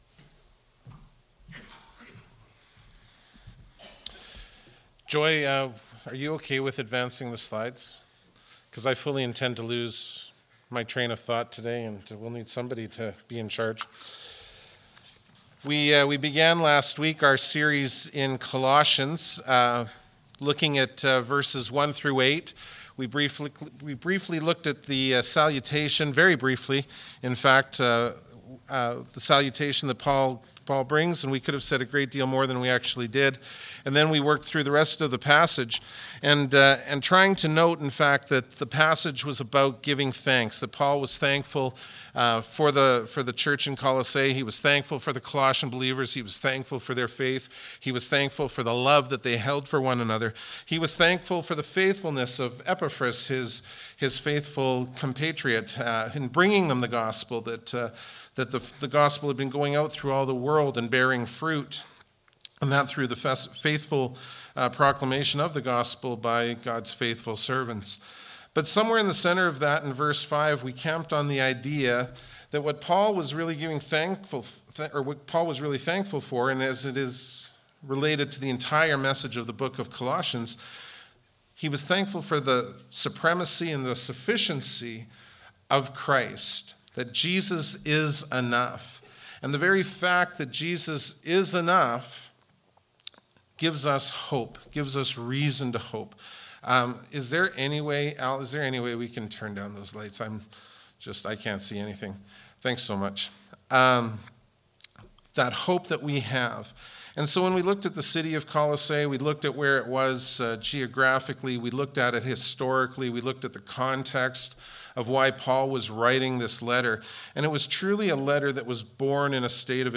Bible Text: Colossians 1:9-14 | Preacher